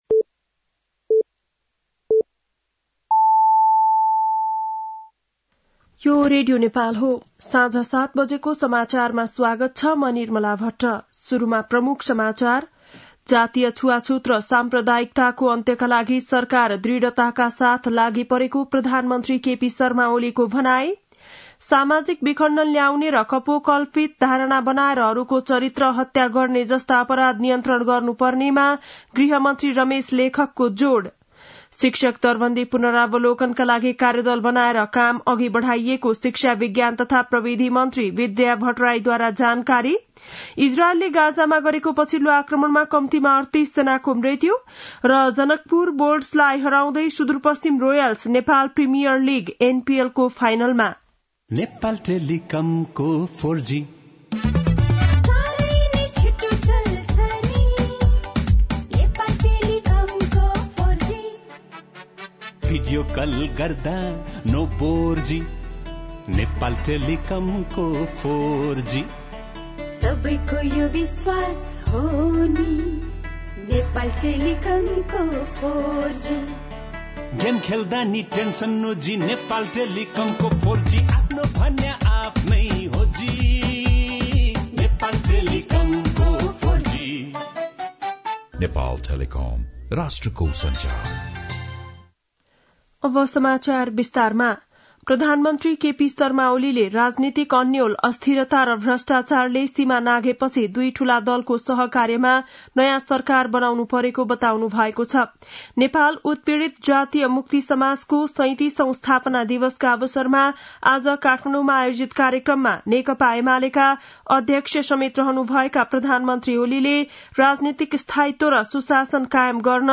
बेलुकी ७ बजेको नेपाली समाचार : ४ पुष , २०८१
7-PM-Nepali-NEWS-9-3.mp3